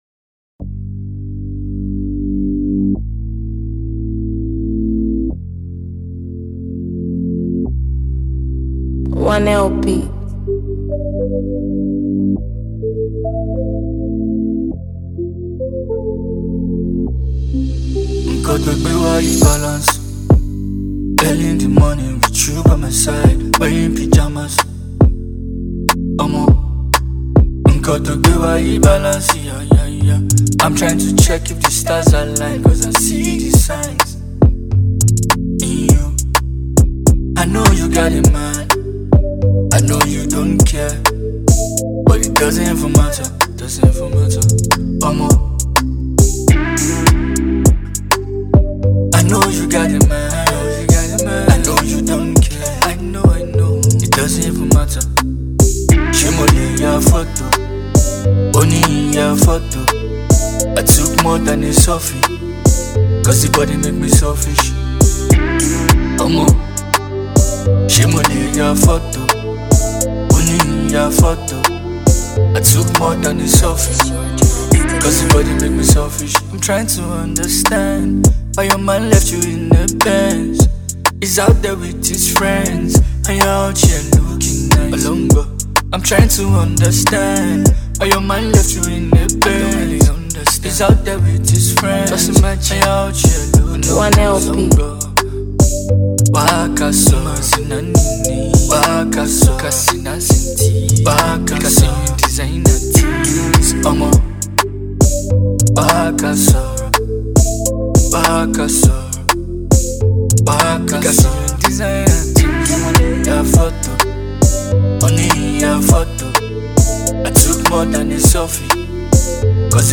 Primarily an Afropop artist
opens with a solemn hum, setting a confessional tone